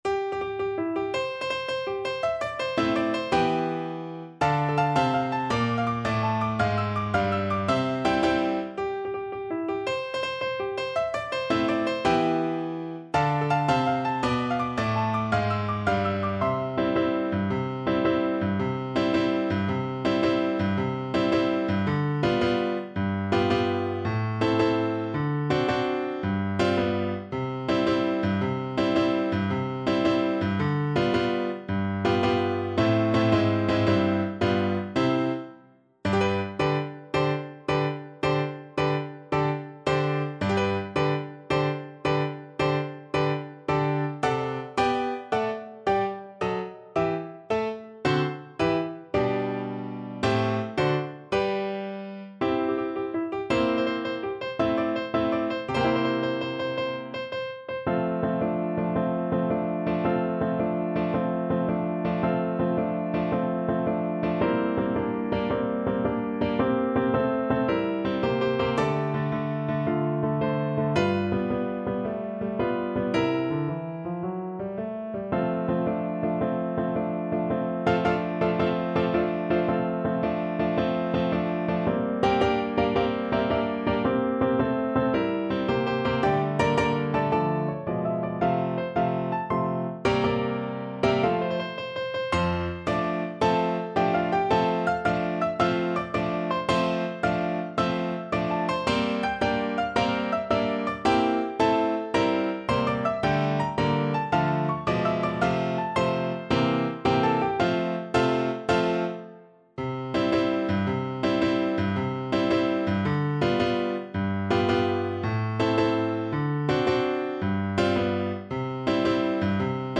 6/8 (View more 6/8 Music)
March .=c.110